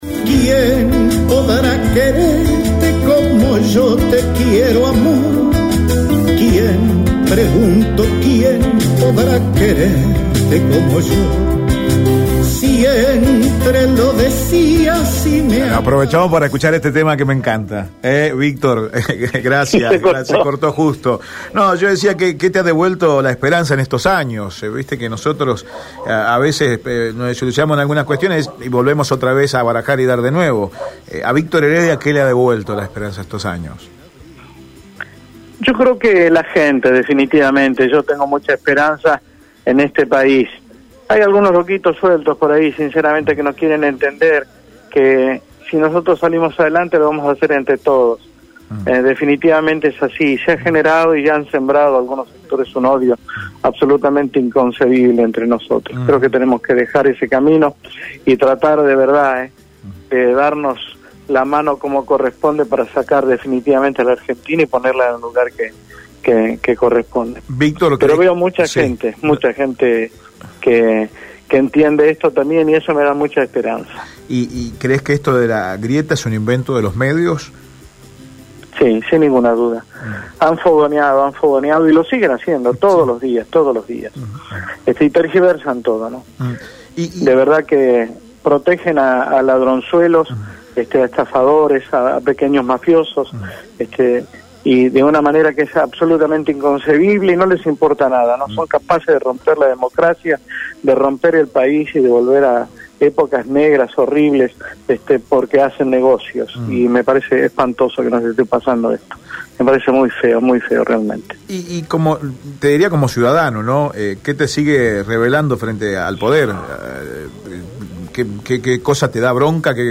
la extensa charla con la leyenda de la música latina